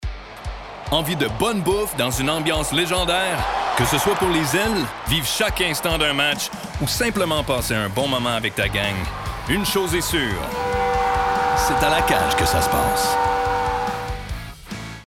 Publicité (La Cage) - FR